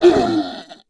Index of /App/sound/monster/orc_black
fall_1.wav